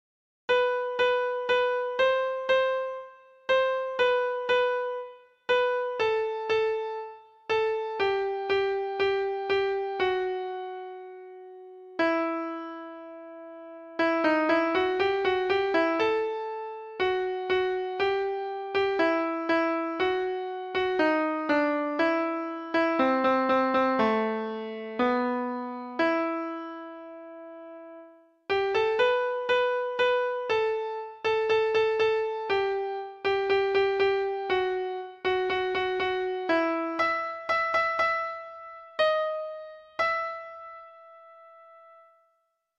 Folk Songs
(Round)